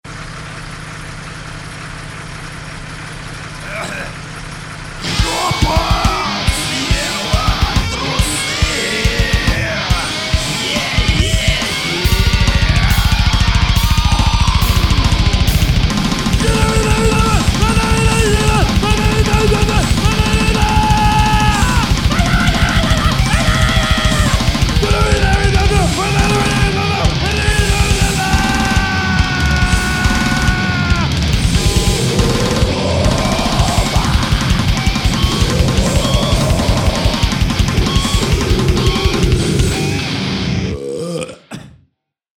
Видимо, послушав эту весьма занимательную и содержательную композицию в стиле Super-puper brutal grind metal, некоторым отверстиям человеческого тела становится так грустно, ажно вообще пиздец...